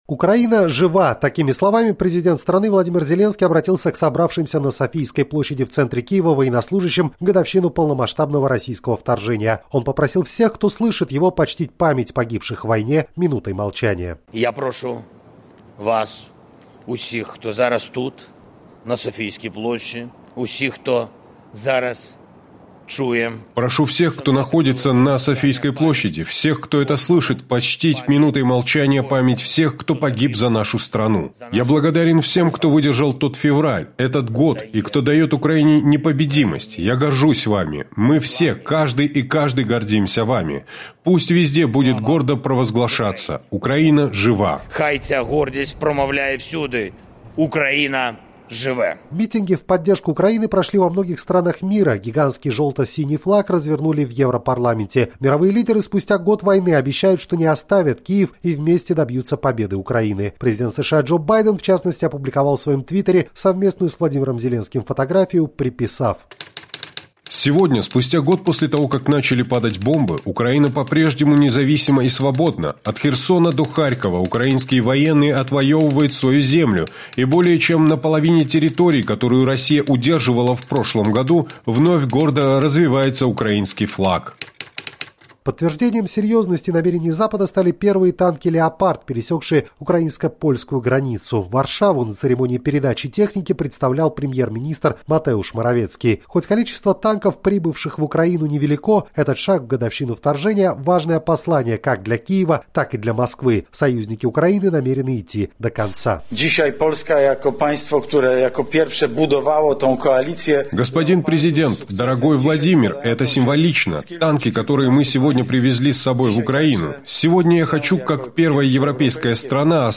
«Украина – жива!» – такими словами президент страны Владимир Зеленский обратился к собравшимся на Софийской площади в центре Киева военнослужащим в годовщину полномасштабного российского вторжения.